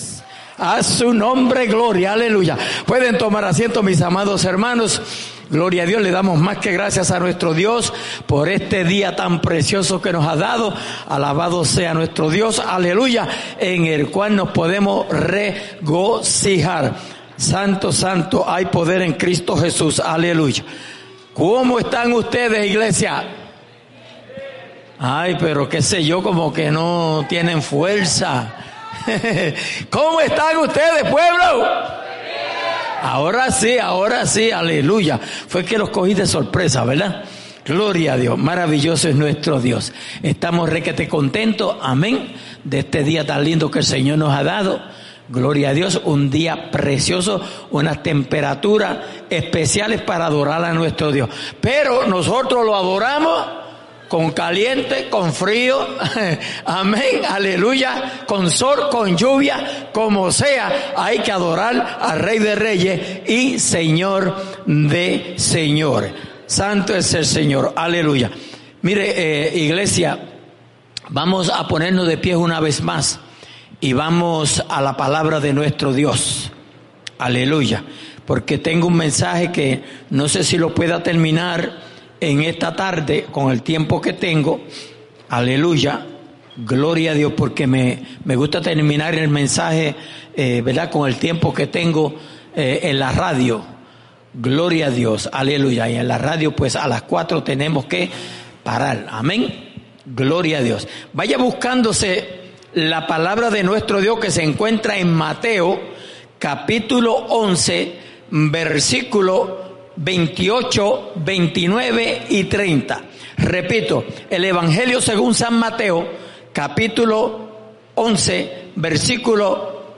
en la Iglesia Misión Evangélica en Souderton, PA